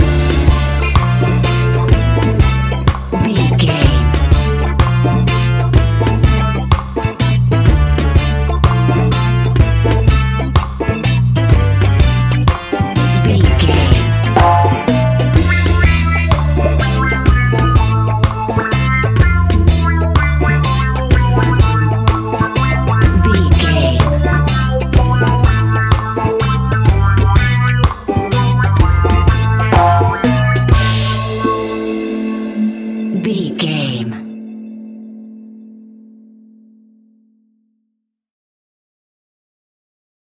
Classic reggae music with that skank bounce reggae feeling.
Aeolian/Minor
laid back
chilled
off beat
skank guitar
hammond organ
percussion
horns